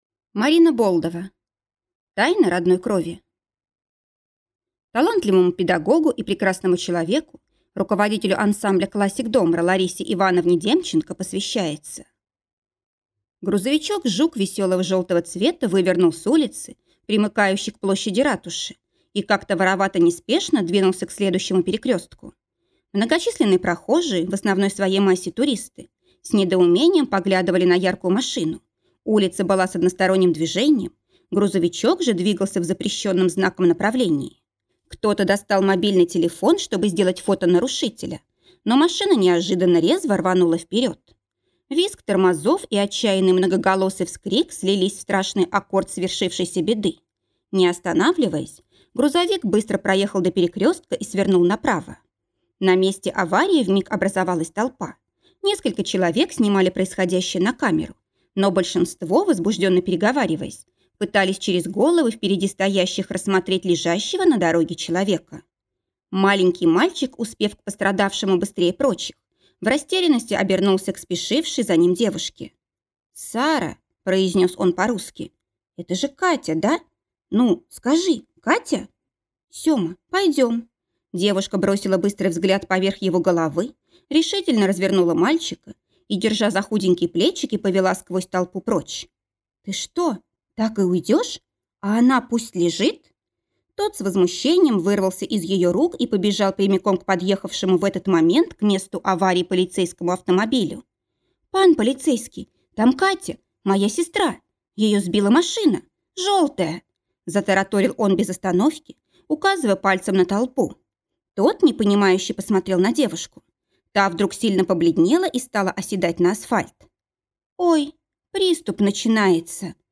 Аудиокнига Тайна родной крови | Библиотека аудиокниг